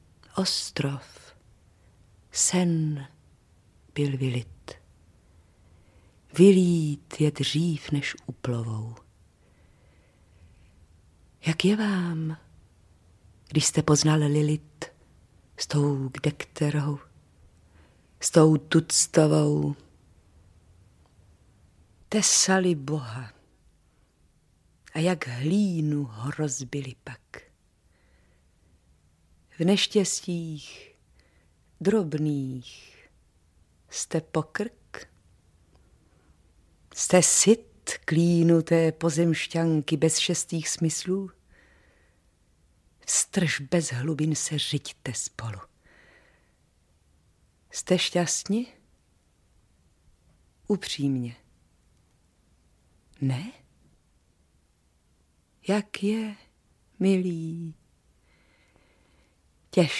Audiobook
Audiobooks » Poetry